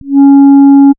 Il permet la simulation temporelle du champ de pression dans le résonateur à partir d'une description modale de la colonne d'air et pour des paramètres de contrôle variables au cours du temps.
Montee_lente.wav